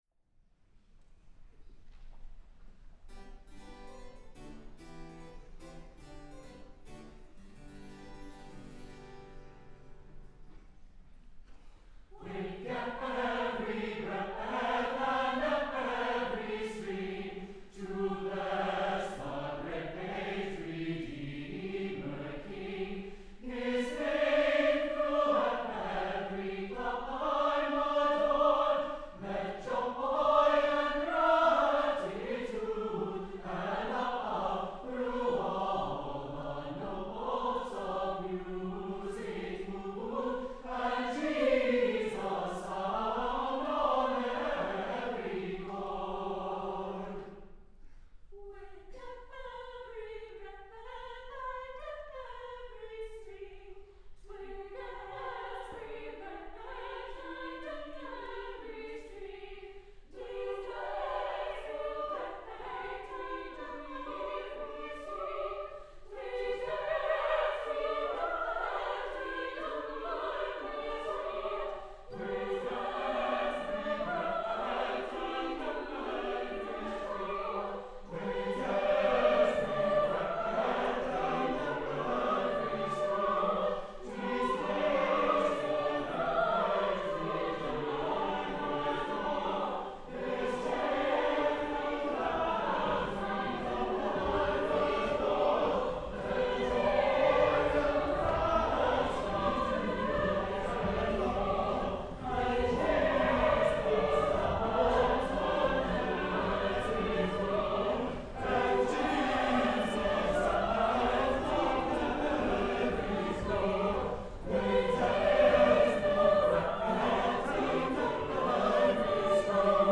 Here is another canon by William Billings (1746-1800): Wake Ev’ry Voice, that also includes a rare “Continuo” part.